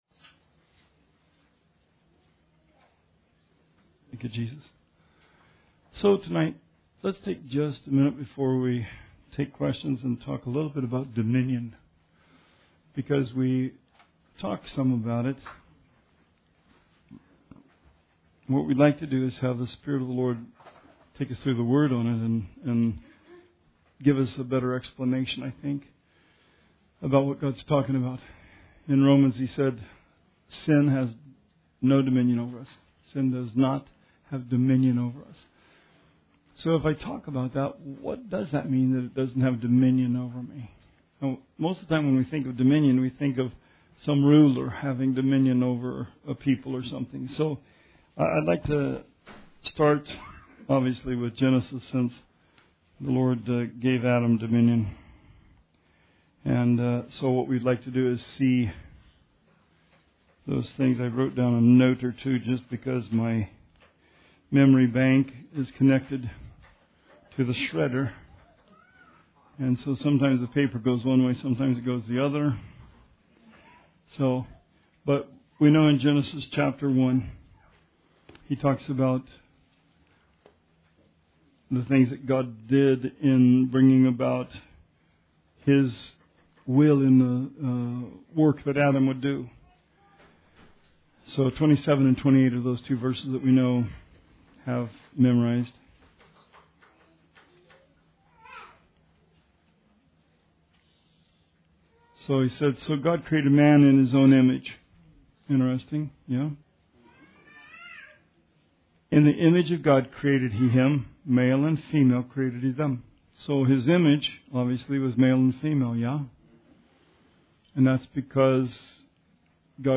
Bible Study 6/13/18